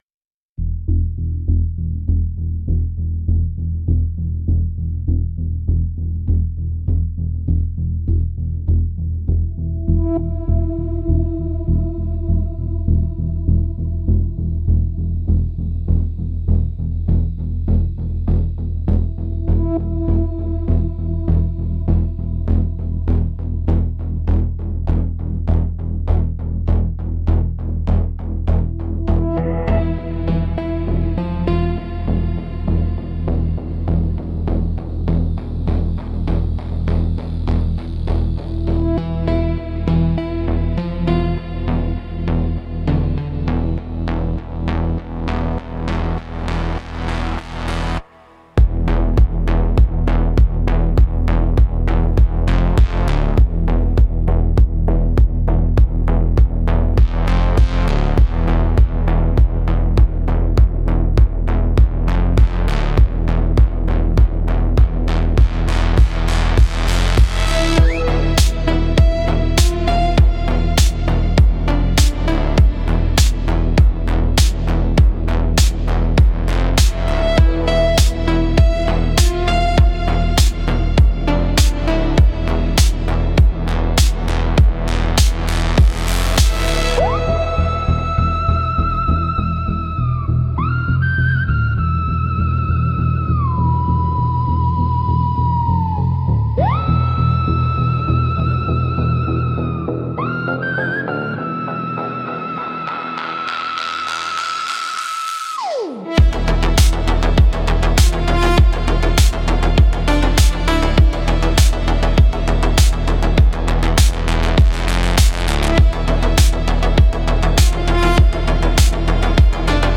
Instrumentals - The Slow Unspooling